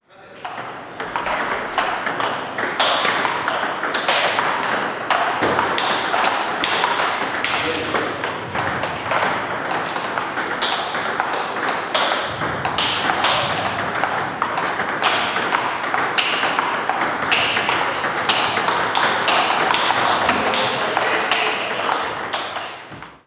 Ambiente polideportivo durante un partido de ping pong
ping-pong
Sonidos: Gente
Sonidos: Deportes